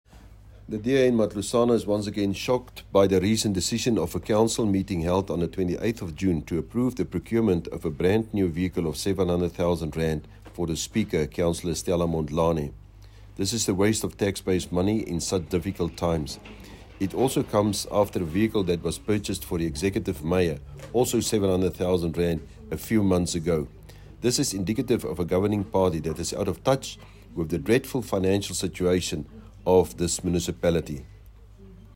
Issued by Cllr Johannes Le Grange – DA Caucus Leader: City of Matlosana
Note to Editors: Please find attached soundbites in